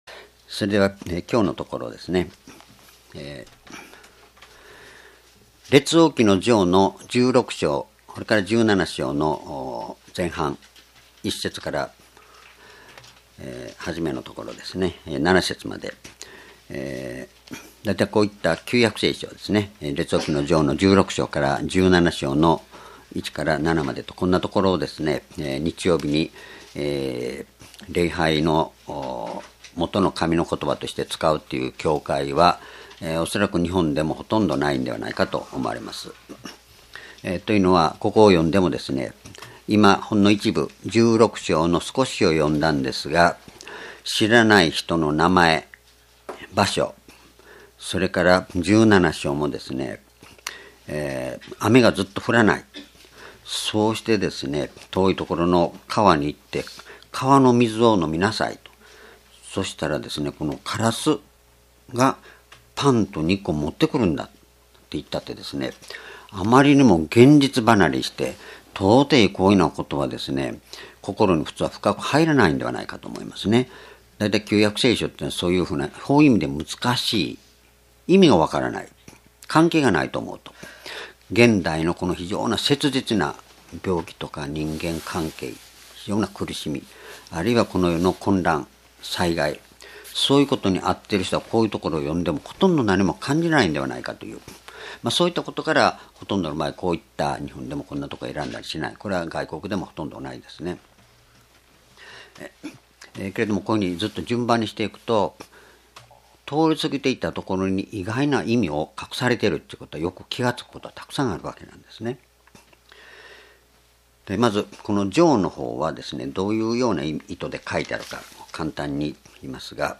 主日礼拝日時 2017年3月5日 聖書講話箇所 列王記上 16-17章1-7 「闇の中の光としてのエリヤ」 ※視聴できない場合は をクリックしてください。